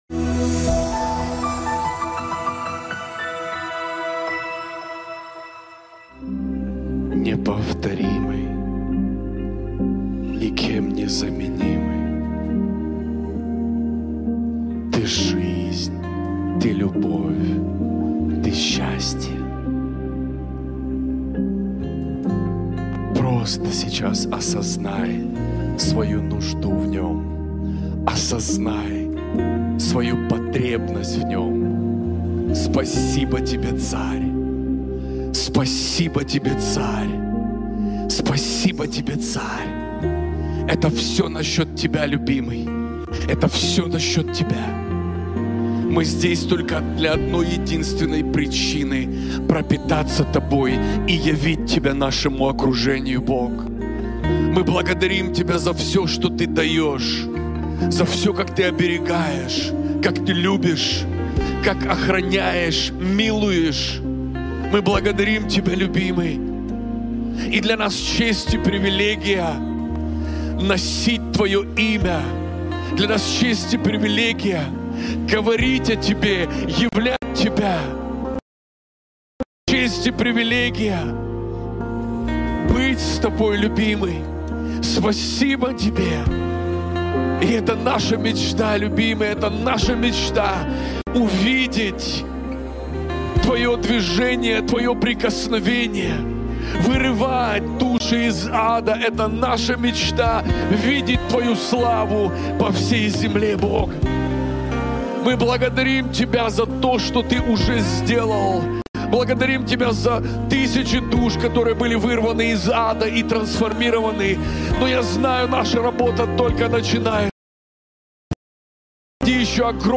МОЛИТВЫ ЦЕНТРА ТРАНСФОРМАЦИИ